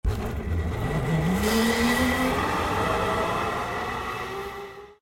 ziplineFull.ogg